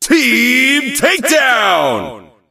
teamtakedown_vo_01.ogg